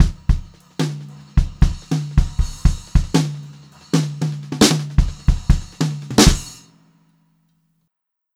152H2FILL3-L.wav